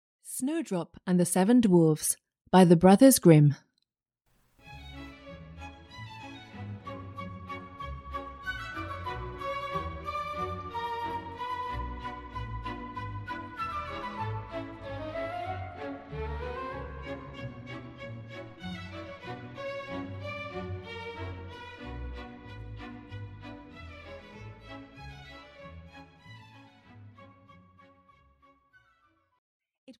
Audio knihaSnow White and the Seven Dwarfs, a Fairy Tale (EN)
Ukázka z knihy